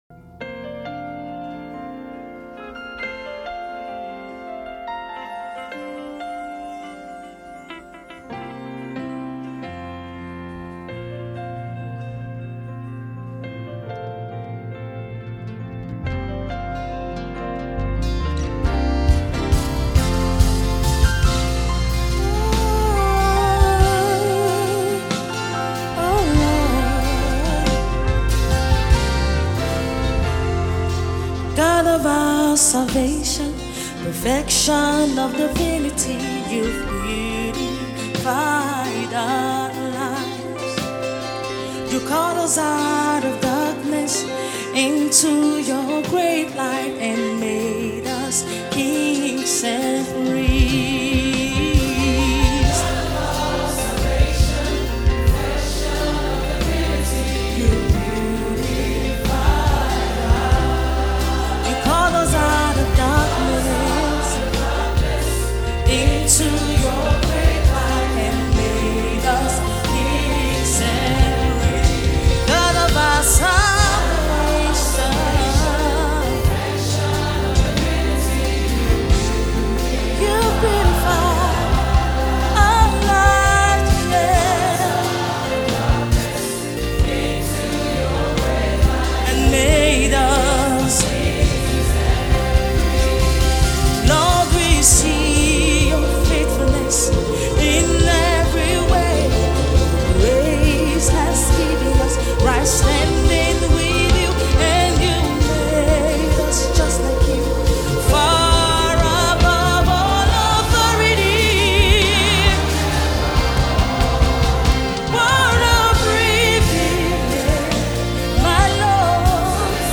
Lyrics, Praise and Worship